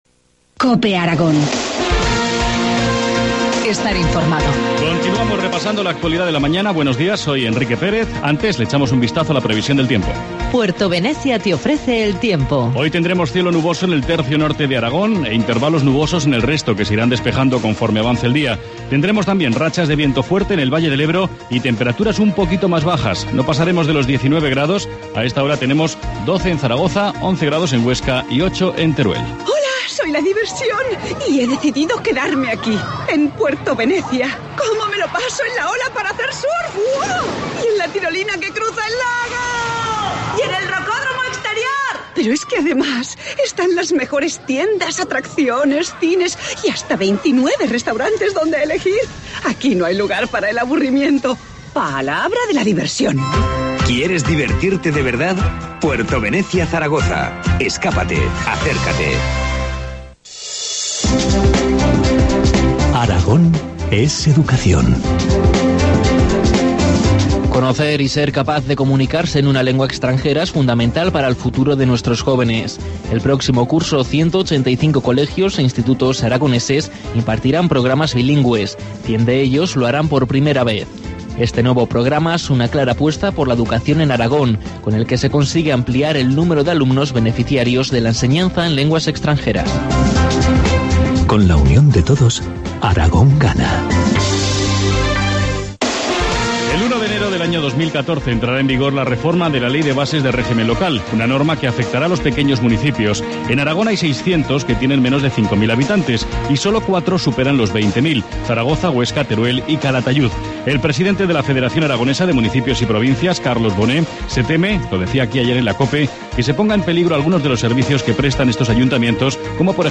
Informativo matinal, jueves 23 de mayo, 8.25 horas